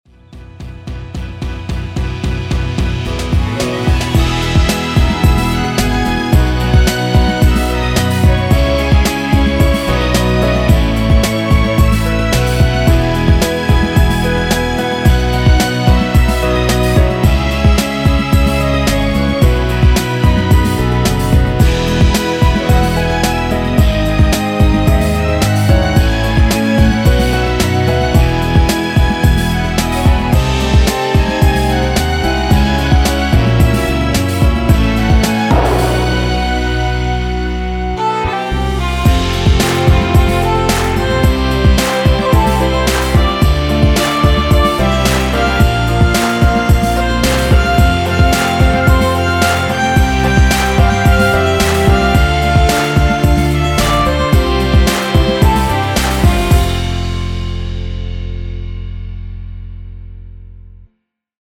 원곡에서 4분 45초까지하고 엔딩을만들어 놓았습니다.(미리듣기 확인)
원키에서(-1)내린 멜로디 포함된 MR입니다.
Db
앞부분30초, 뒷부분30초씩 편집해서 올려 드리고 있습니다.
중간에 음이 끈어지고 다시 나오는 이유는